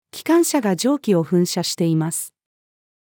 機関車が蒸気を噴射しています。-female.mp3